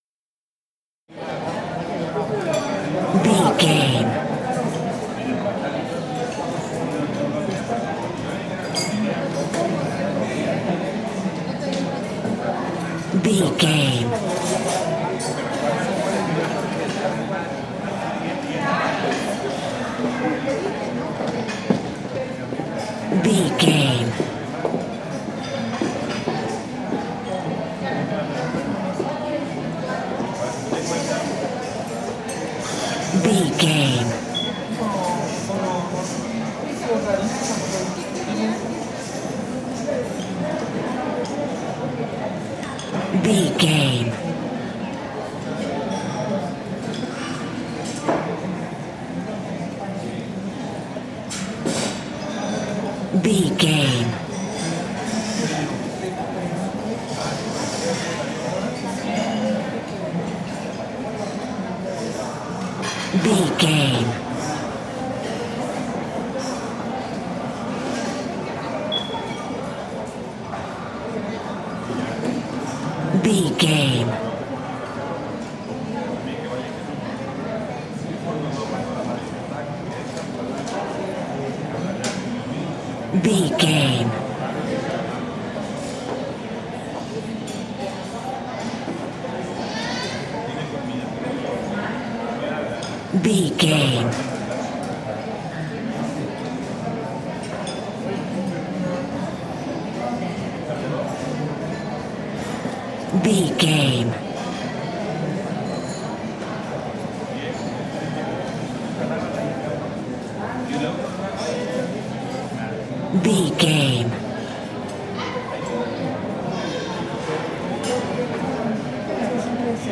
Restaurant crowd large
Sound Effects
urban
chaotic
ambience